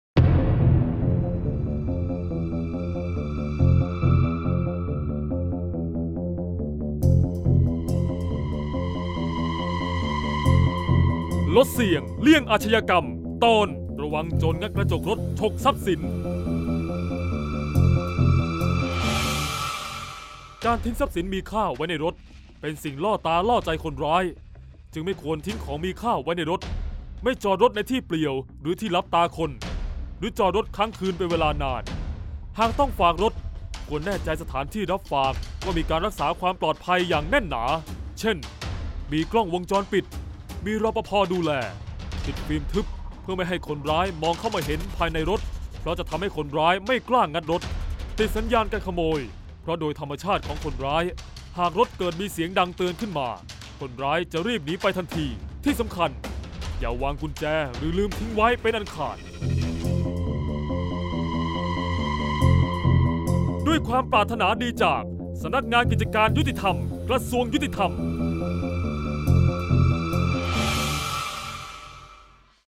เสียงบรรยาย ลดเสี่ยงเลี่ยงอาชญากรรม 37-ระวังโจรงัดกระจกรถ